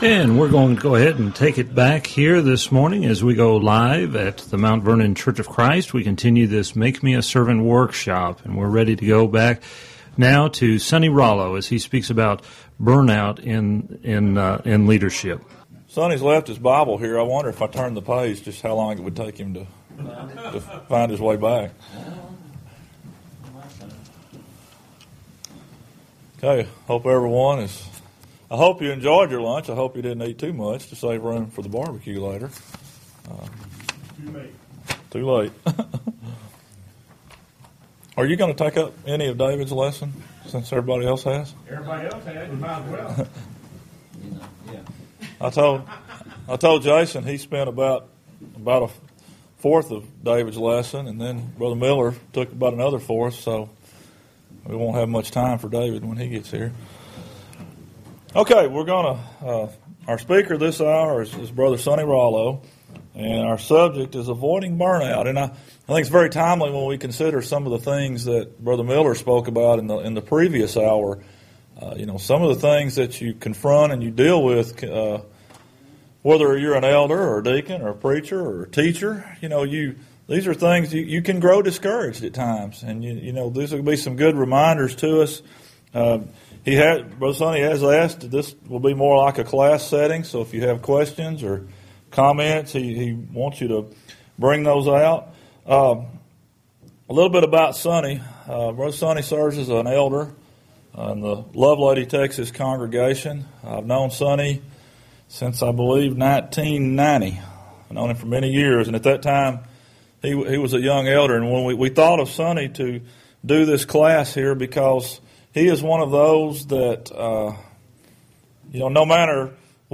Make Me A Servant: A Workshop for Those Who Lead and Serve